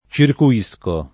circuire [©irku-&re] v. («girare; aggirare; raggirare»); circuisco [